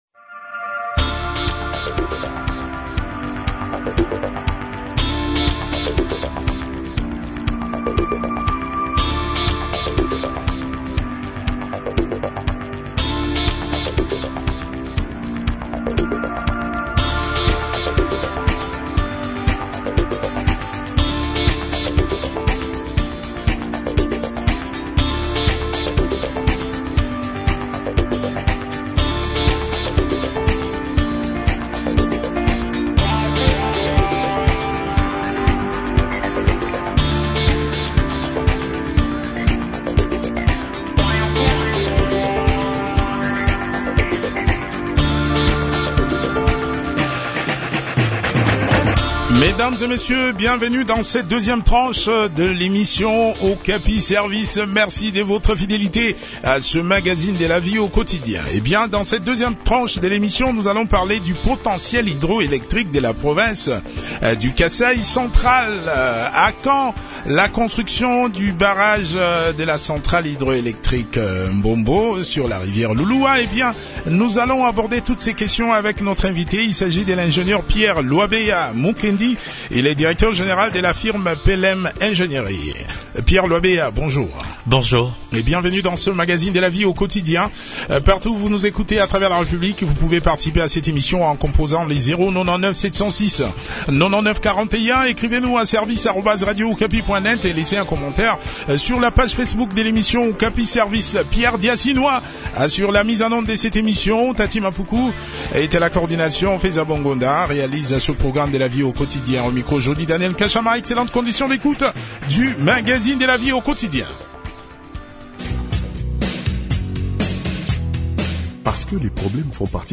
Réponses dans cet entretien